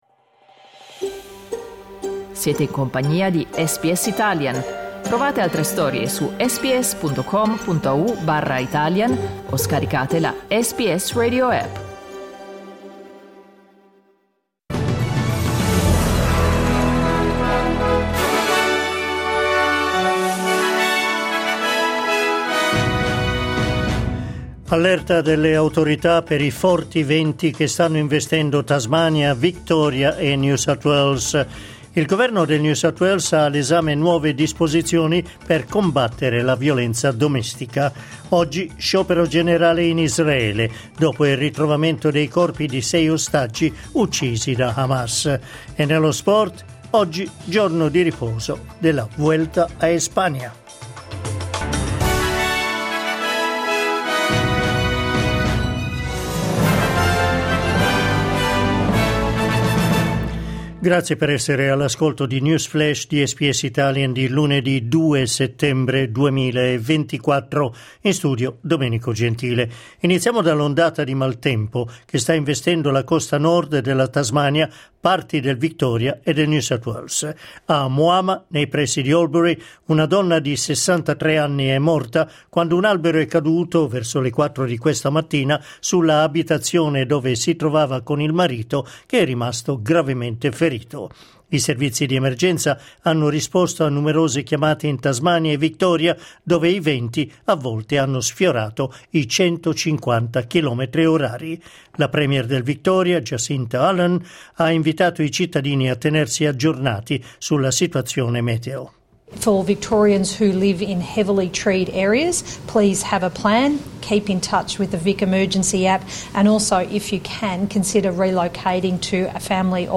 News flash lunedì 2 settembre 2024